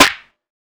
18 clap hit.wav